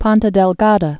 (pŏntə dĕl-gädə, pôɴ-)